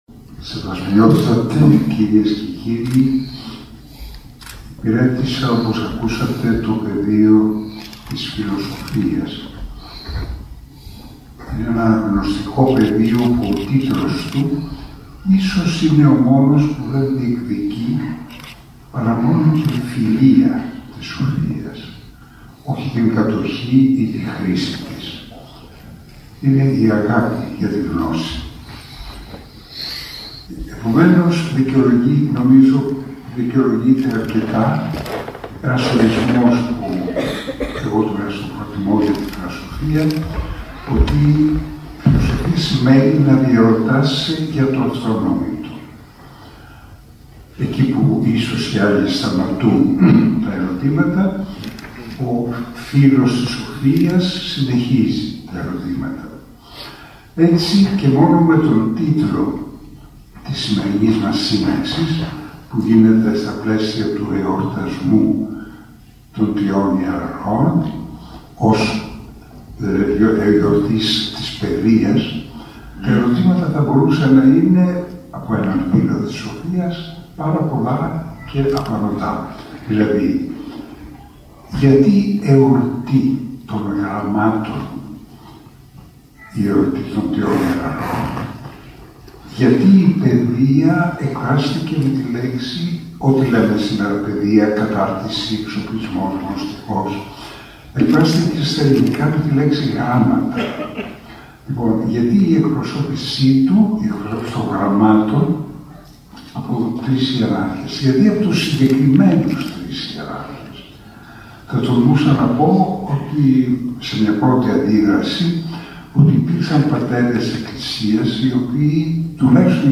Επιστημονική Εσπερίδα επί τη ευκαιρία του εορτασμού των Τριών Ιεραρχών συνδιοργάνωσαν η Ιερά Μητρόπολη Κεφαλληνίας με τις Δ/νσεις Α/θμιας & Β/μιας Εκπ/σης Κεφαλληνίας, τα τμήματα του Ιονίου Πανεπιστημίου στο Νομό και με την στήριξη της ΚΕ.ΔΗ.ΚΕ (ΚΟΙΝΩΦΕΛΗΣ ΕΠΙΧΕΙΡΗΣΗ ΔΗΜΟΥ ΚΕΦΑΛΛΟΝΙΑΣ) στο Δημοτικό Θέατρο “Ο Κέφαλος” στο Αργοστόλι.